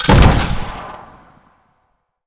sci-fi_weapon_rifle_large_shot_04.wav